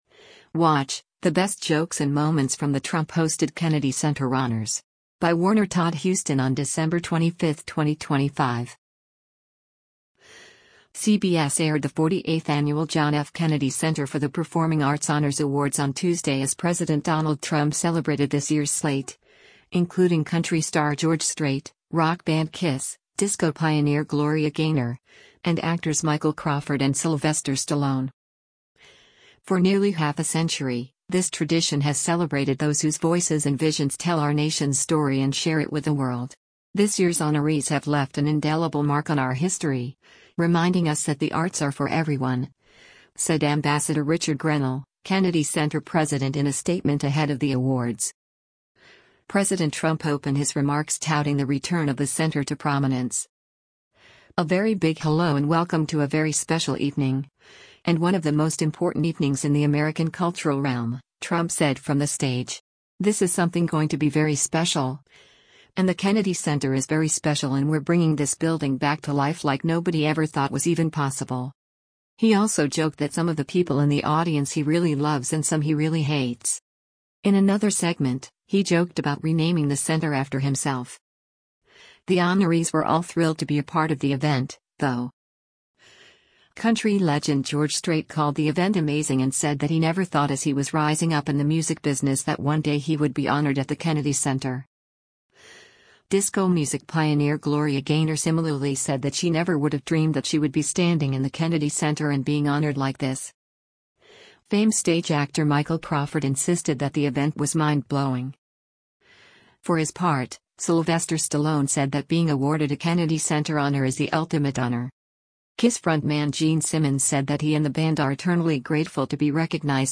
“A very big hello and welcome to a very special evening, and one of the most important evenings in the American cultural realm,” Trump said from the stage.